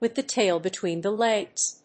アクセントwith the [one's] táil betwèen the [one's] légs